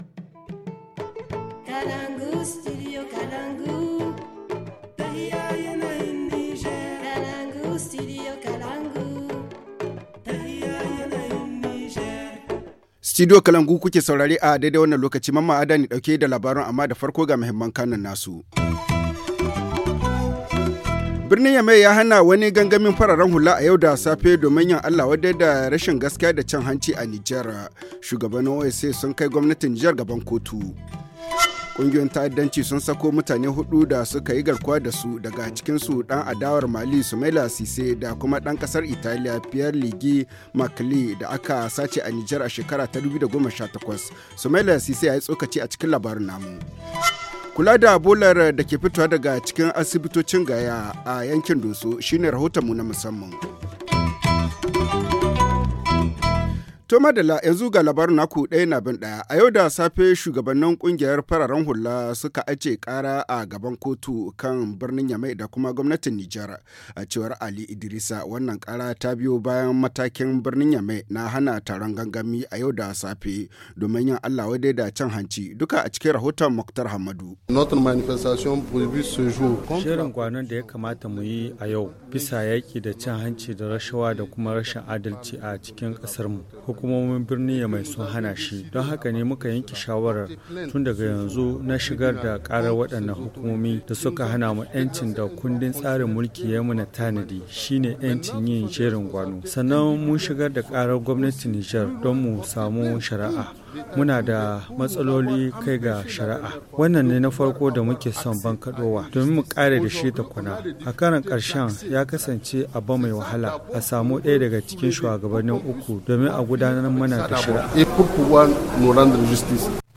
Le journal du 09 octobre 2020 - Studio Kalangou - Au rythme du Niger